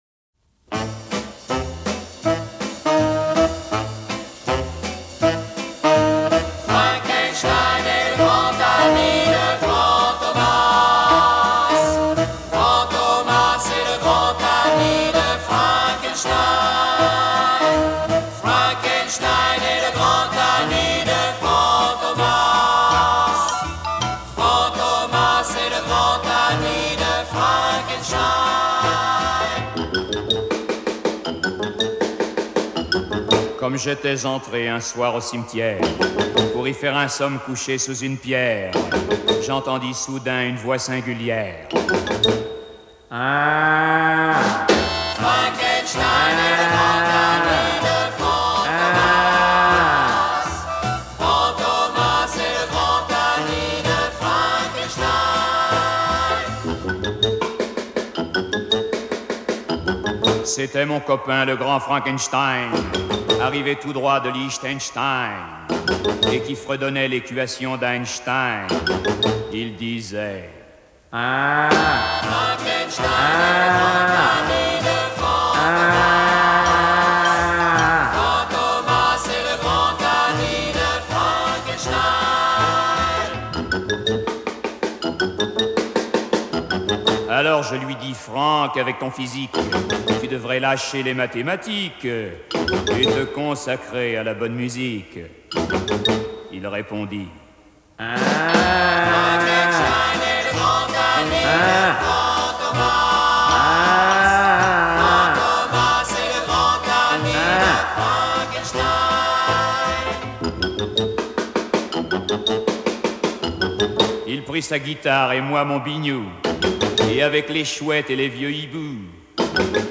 poussant même des grognements associés au monstre